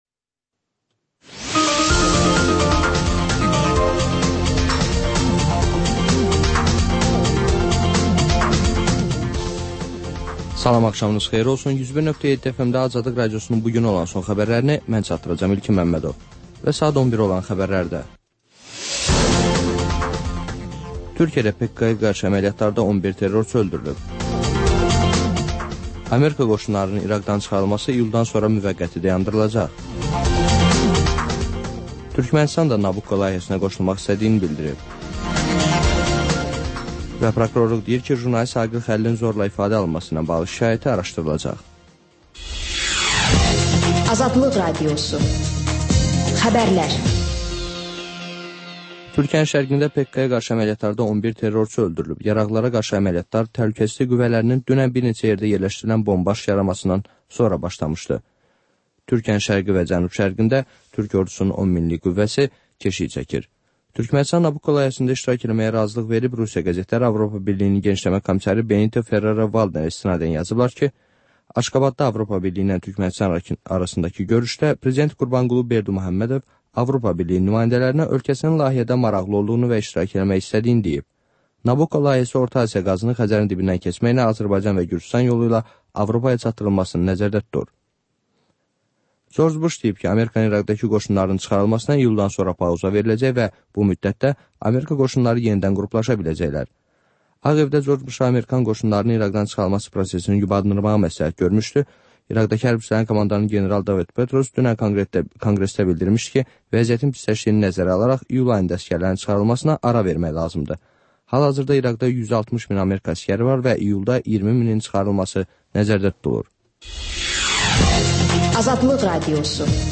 Xəbərlər, müsahibələr, hadisələrin müzakirəsi, təhlillər, sonda QAFQAZ QOVŞAĞI rubrikası: «Azadlıq» Radiosunun Azərbaycan, Ermənistan və Gürcüstan redaksiyalarının müştərək layihəsi